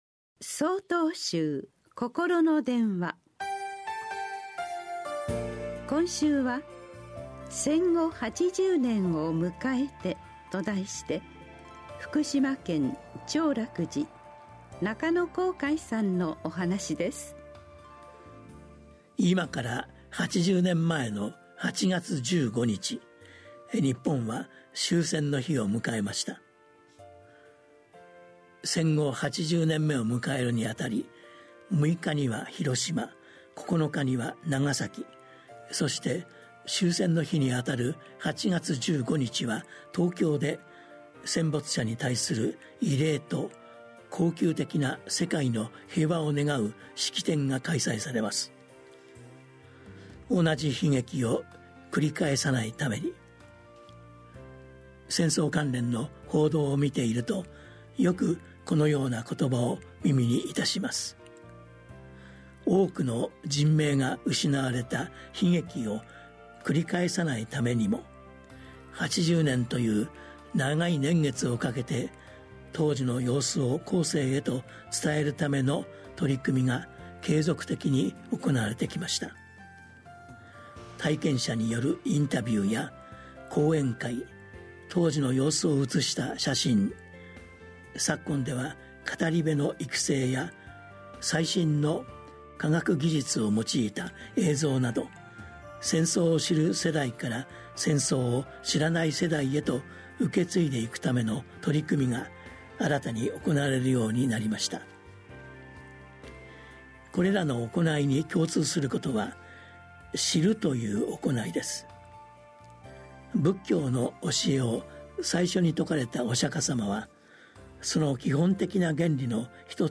心の電話（テレホン法話）８/５公開『戦後80年を迎えて』 | 曹洞宗 曹洞禅ネット SOTOZEN-NET 公式ページ